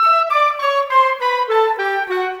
Rock-Pop 20 Clarinet, Flute _ Oboe 01.wav